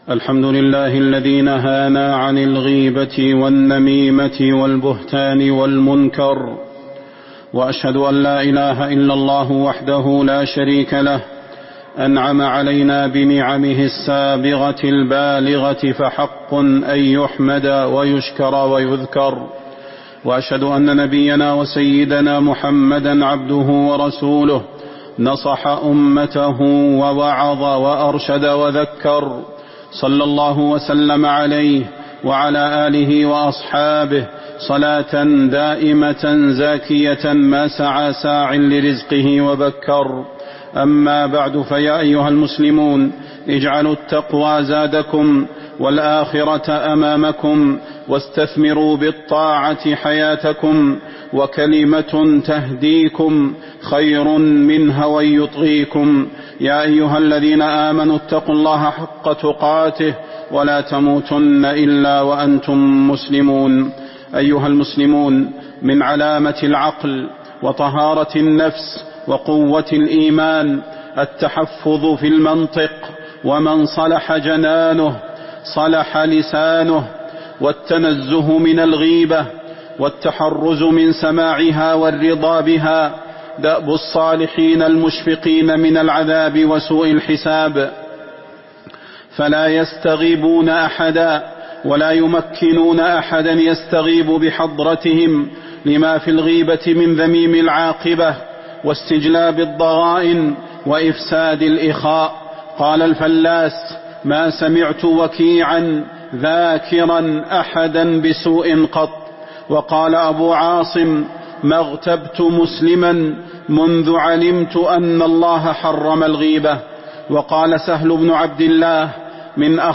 تاريخ النشر ٦ جمادى الأولى ١٤٤٦ هـ المكان: المسجد النبوي الشيخ: فضيلة الشيخ د. صلاح بن محمد البدير فضيلة الشيخ د. صلاح بن محمد البدير النهي عن الغيبة